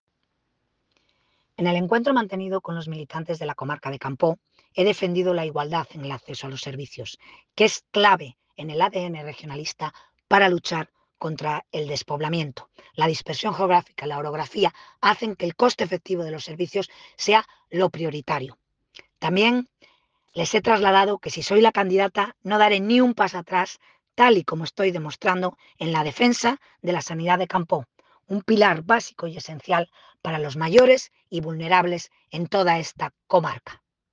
Así lo ha defendido hoy en un acto con la militancia del Comité Comarcal de Campoo celebrado en Reinosa, donde ha subrayado que garantizar los mismos derechos y oportunidades para todos los ciudadanos, vivan donde vivan, es una “prioridad irrenunciable” para el proyecto regionalista, y por eso es también una de las diez medidas incluidas en su decálogo de campaña.
audio-campoo_paula-fernandez-viana.mp3